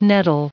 added pronounciation and merriam webster audio
1711_nettle.ogg